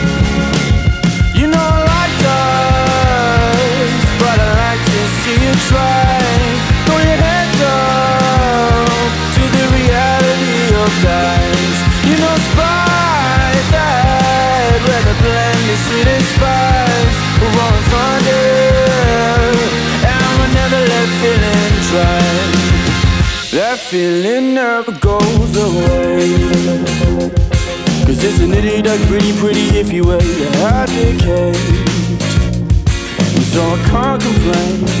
Жанр: Иностранный рок / Рок / Инди